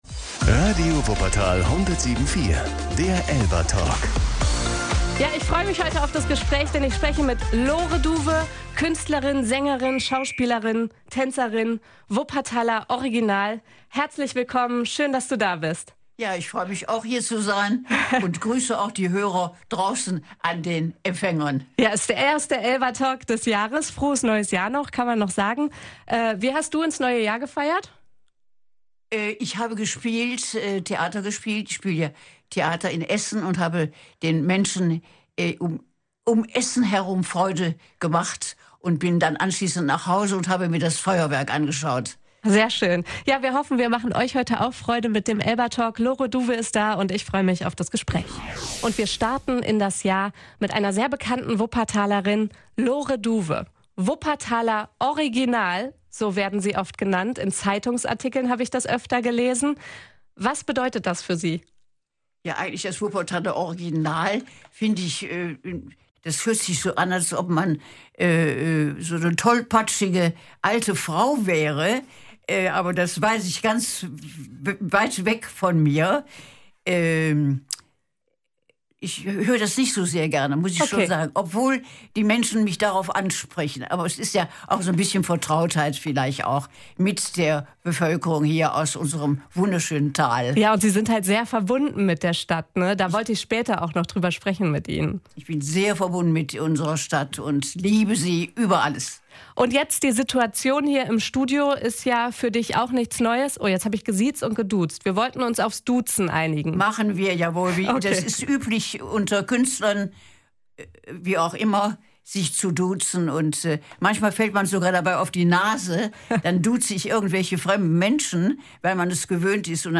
Hört euch das ganze Gespräch hier an.